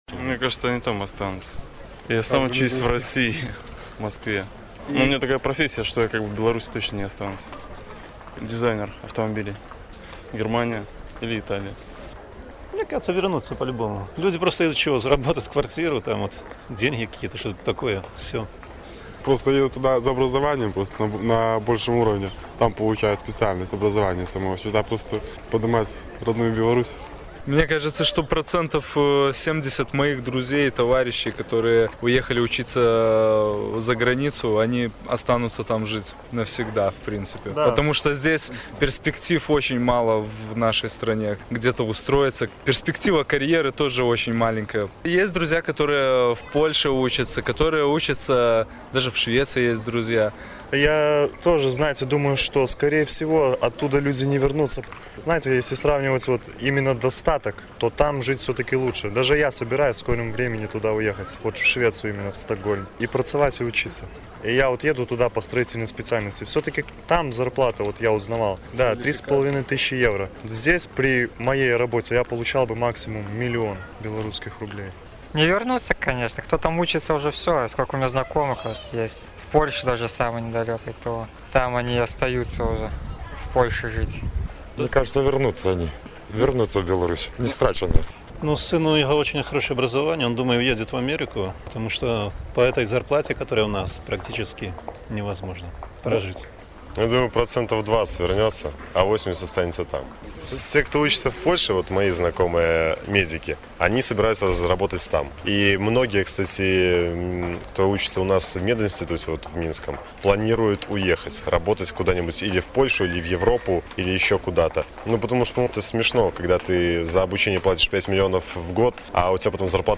Ці варта чакаць, што ў Беларусь вернуцца тыя студэнты, якія зьехалі вучыцца за мяжу? Адказваюць жыхары Горадні.